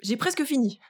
VO_ALL_Interjection_08.ogg